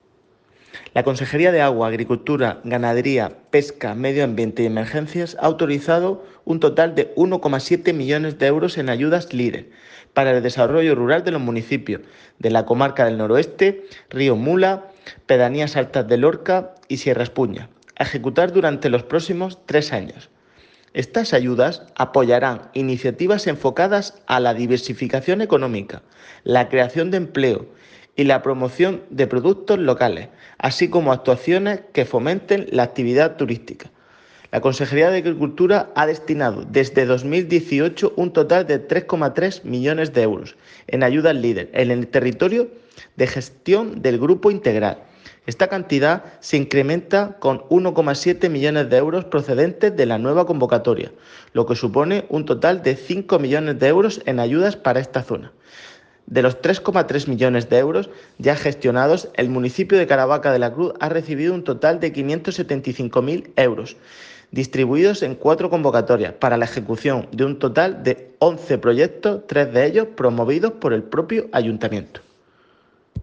Declaraciones del director general de la PAC sobre los proyectos subvencionados en la zona de actividad del Grupo de Acción Local Integral.